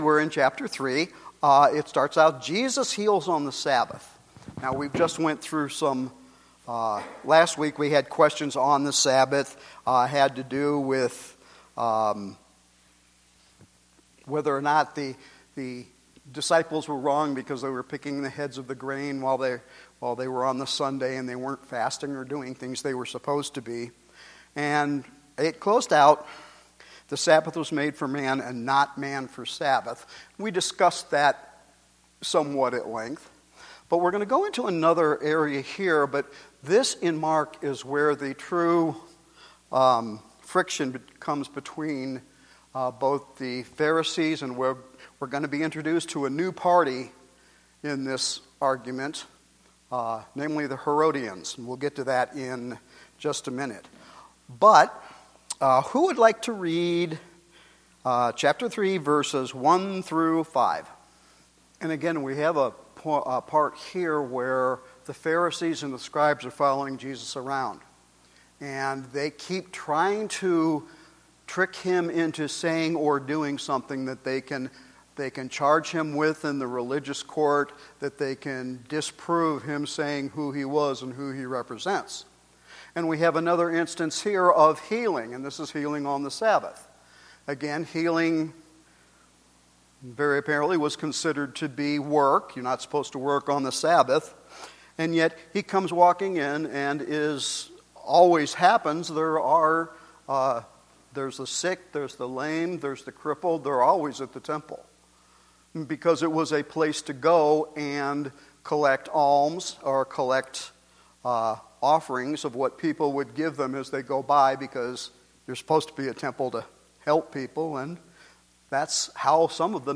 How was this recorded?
Our new Sunday evening Bible study continues with Mark Chapter 3.